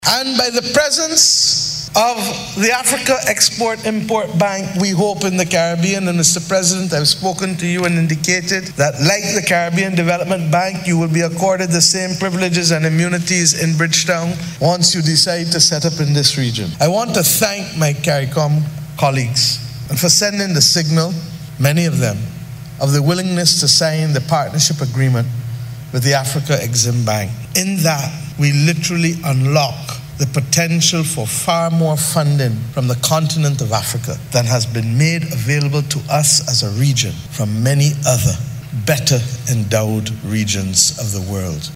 Voice of: Prime Minister, Mia Amor Mottley